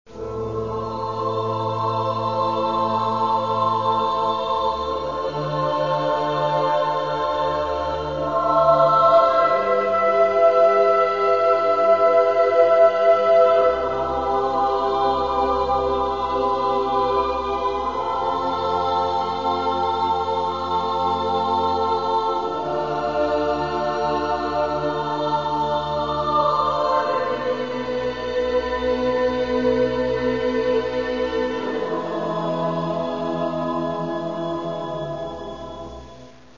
Співають діти